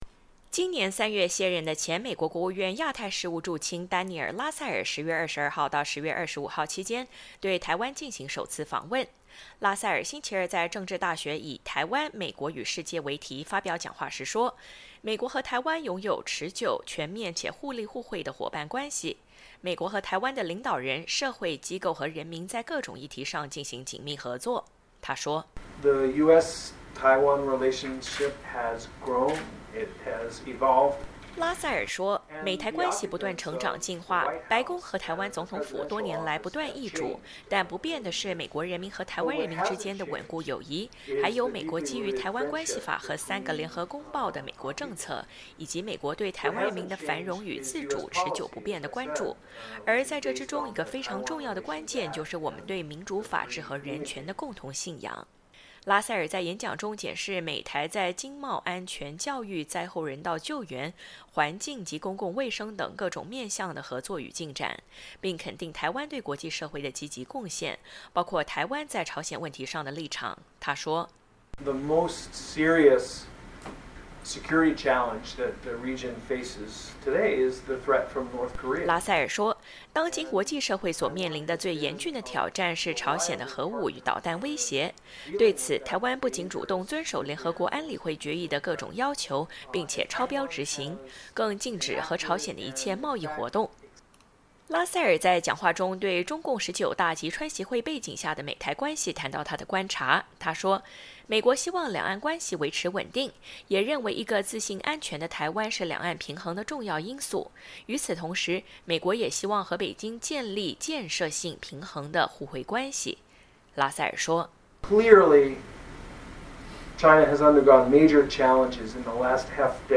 美国国务院前亚太助卿丹尼尔.拉塞尔在台湾政治大学发表演讲